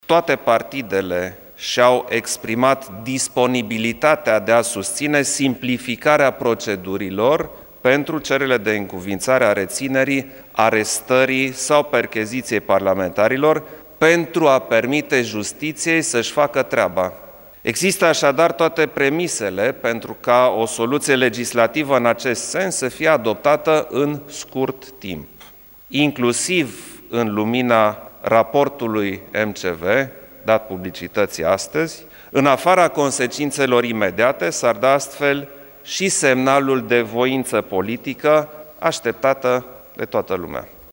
După consultările de la Cotroceni preşedintele Klaus Iohannis a susţinut o declaraţie de presă în care a subliniat că este momentul să trecem la o nouă etapă a democraţiei româneşti, care ar trebui să însemne o resetare a sistemului şi o creştere a încrederii în instituţii.
Între altele Klaus Iohannis a subliniat că toate partidele susţin simplificarea procedurilor pentru a răspunde cererilor justiţiei atunci când sunt anchetaţi parlamentari: